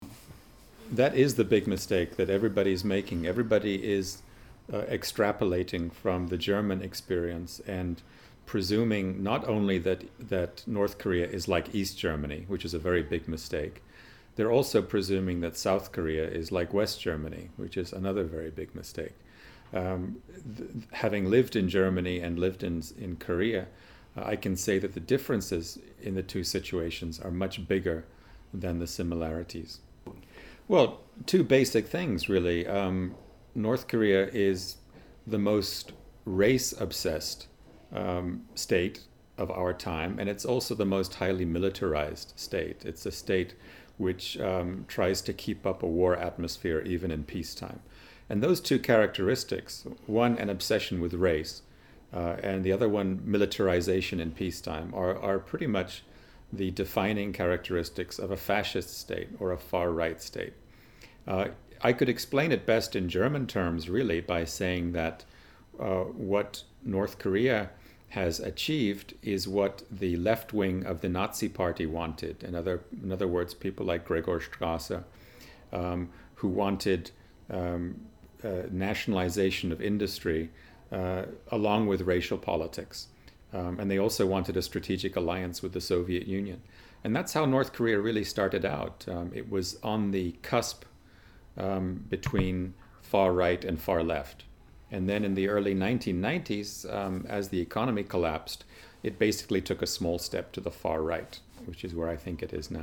Hier ein Auszug aus dem Interview